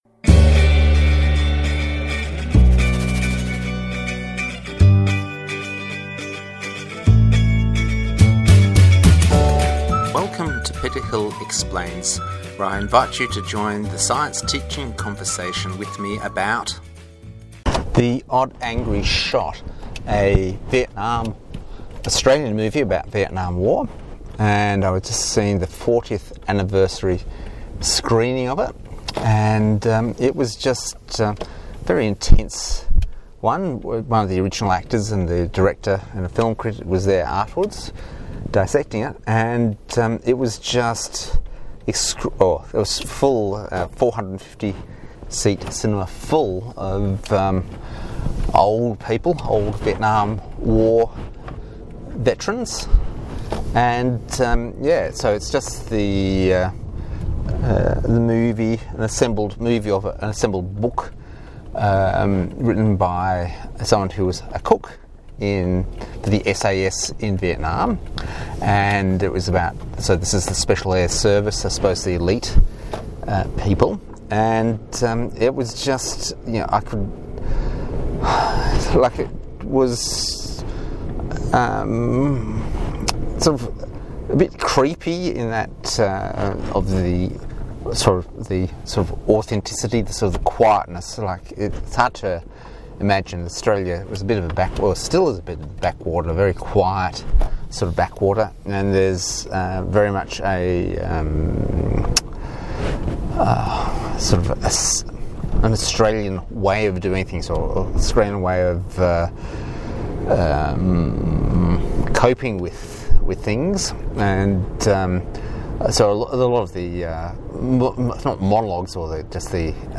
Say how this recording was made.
Review of this classic Vietnam film while driving home.